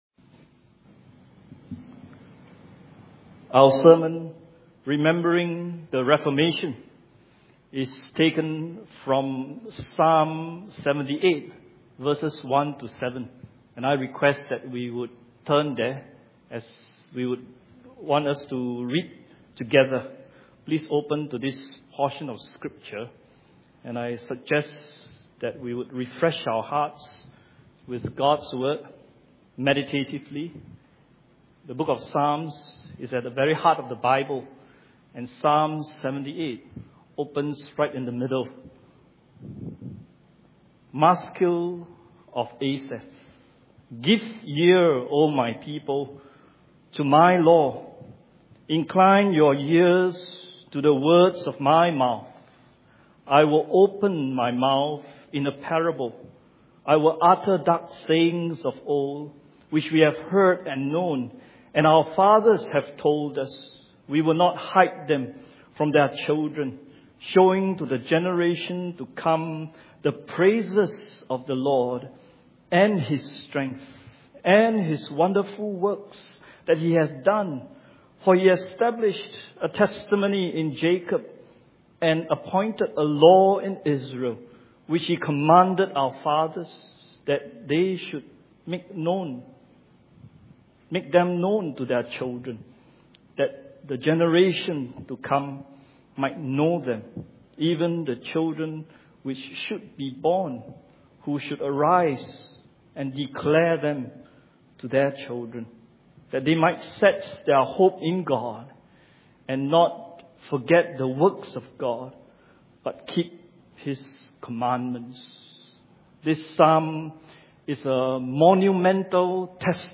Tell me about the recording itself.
Reformation Sunday Service 2014 – Remembering the Reformation